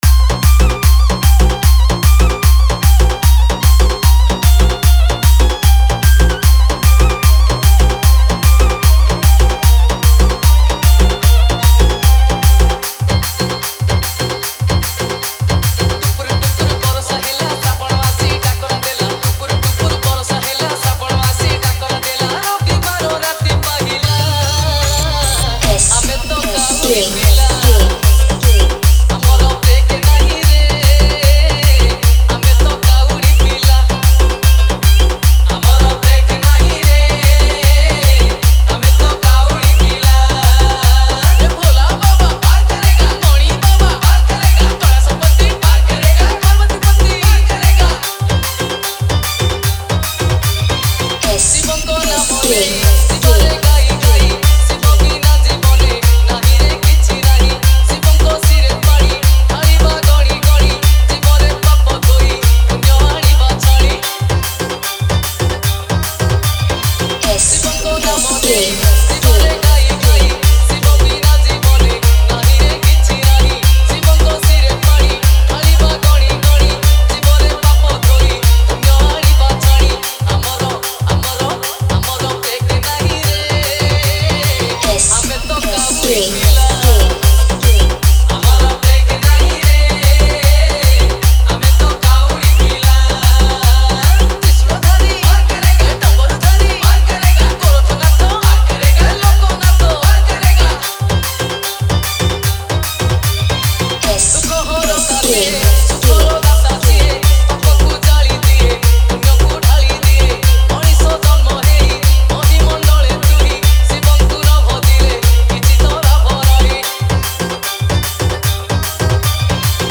Bhajan Dj Remix